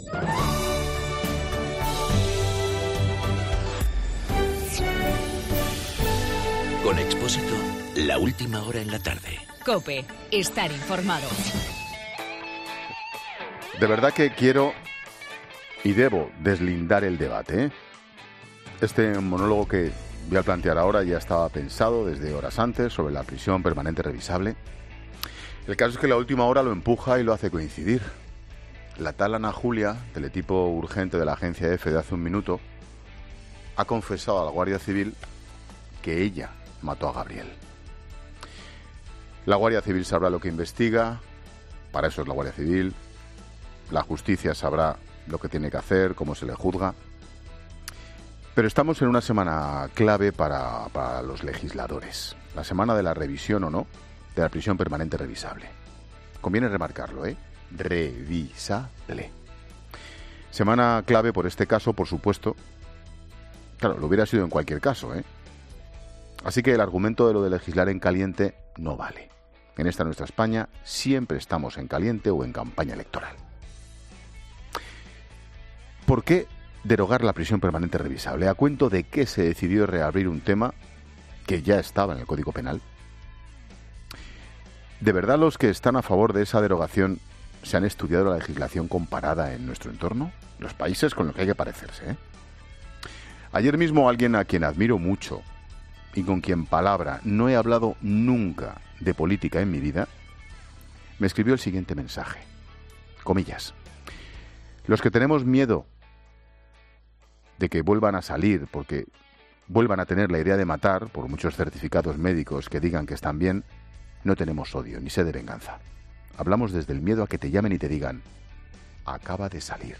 Monólogo de Expósito
El comentario de Ángel Expósito sobre la prisión permanente revisable.